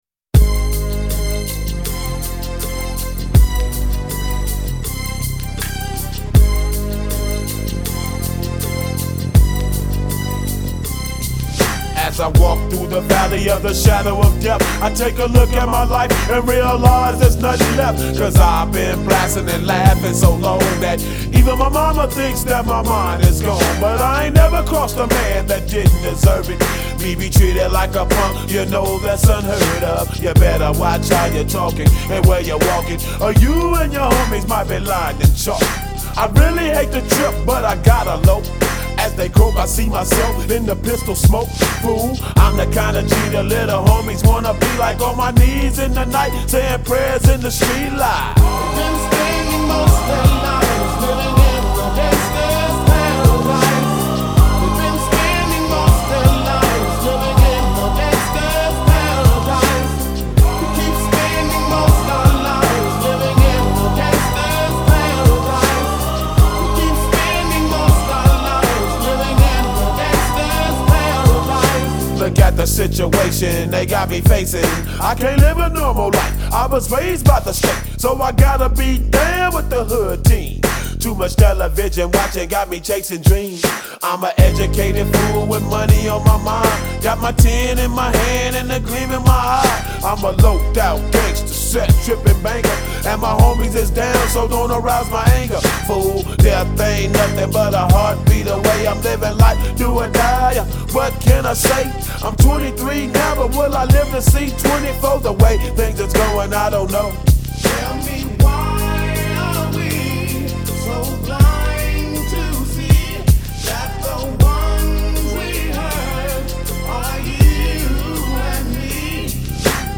HipHop 90er